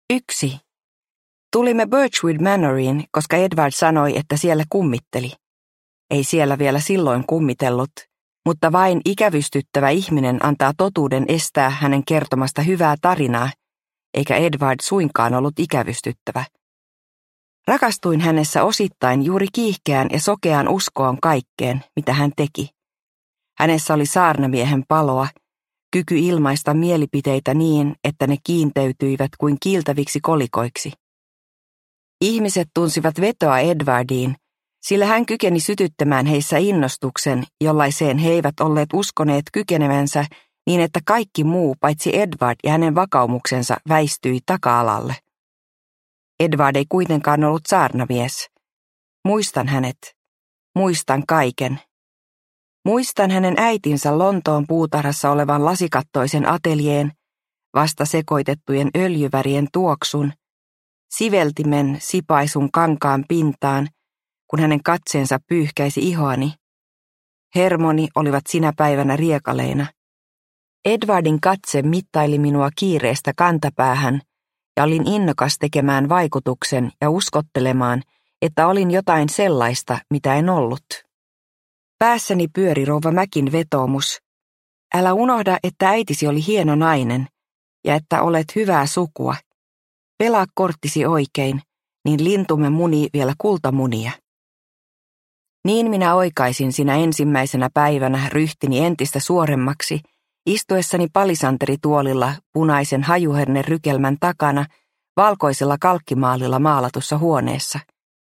Kellontekijän tytär – Ljudbok – Laddas ner